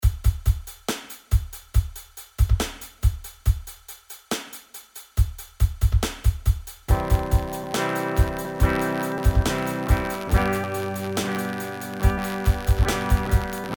Knacken bei Aufnahmen über USB Interface
Nach einigem probieren hab ich es endlich geschafft den Ton vom ME80 in Cubase zu bekommen, aber da ist immer ein knacken dabei das sich echt furchtbar anhört.
Um es verständlicher zu machen hab ich eine kurze MP3 File aufgenommen. der erste Teil ist nur der Groove Agent mit nem einfachen Beat und im zweiten teil spiel ich ein paar clean Akkorde auf der Gitarre. Mann kann es gar nicht überhören.